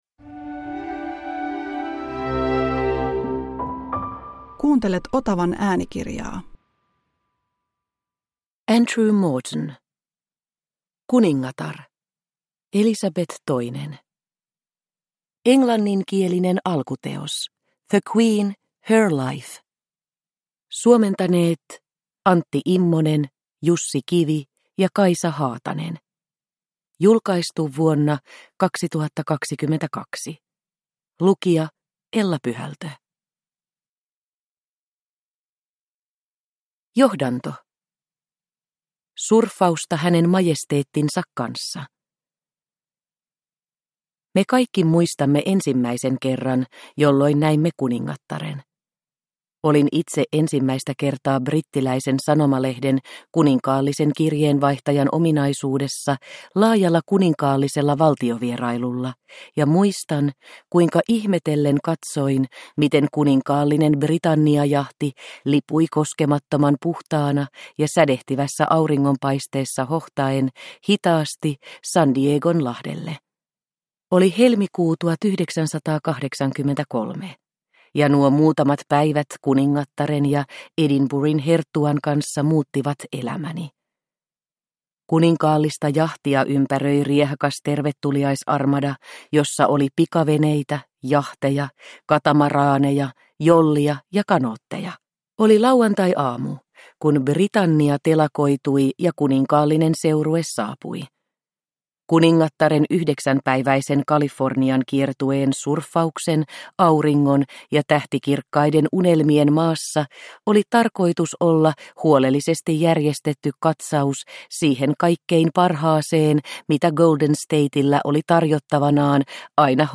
Kuningatar – Ljudbok – Laddas ner